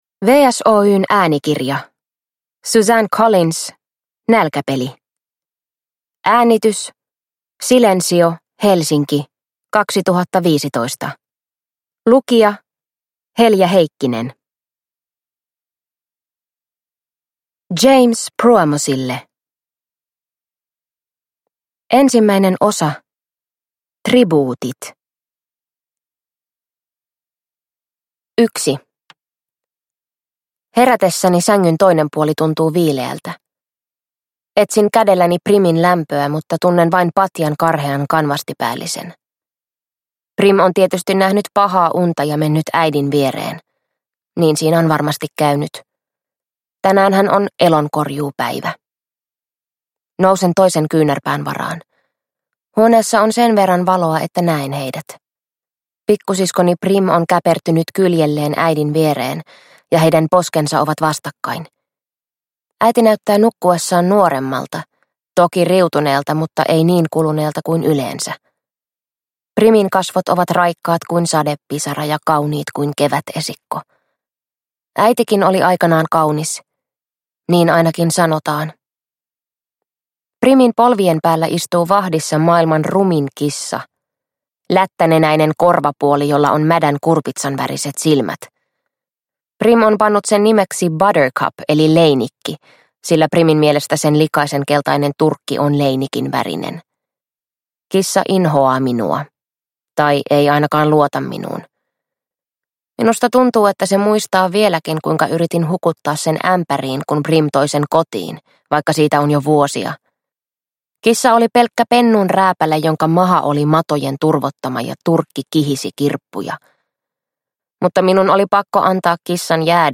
Nälkäpeli – Ljudbok